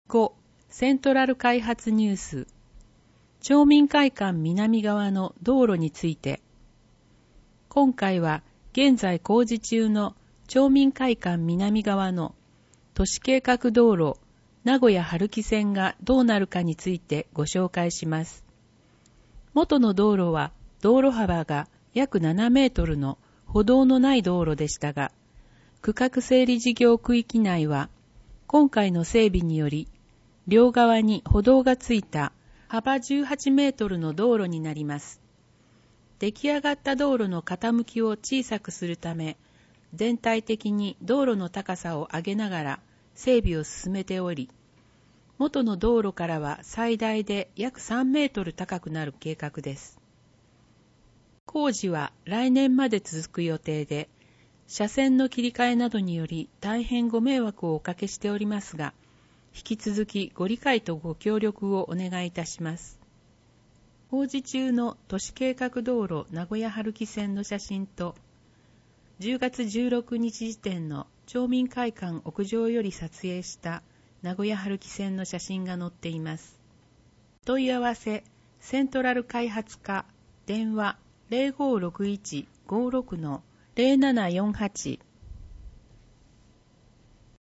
広報とうごう音訳版（2019年12月号）